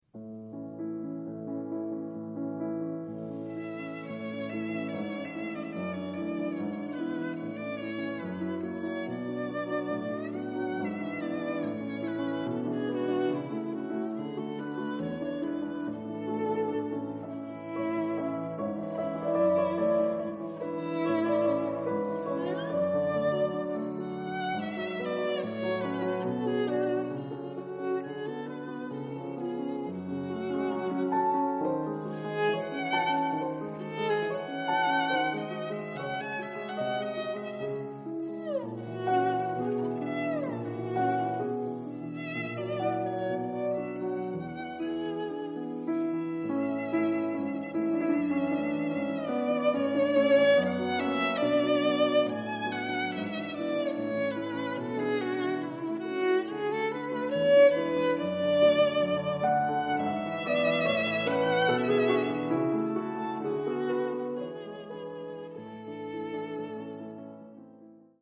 Romance, violon et piano (Paris : Heugel, 1906).
: enregistré par Olivier Charlier, violon et Alexandre Tharaud, piano durant le concert du 5 octobre 2002 au Festival Reynaldo Hahn à Caracas.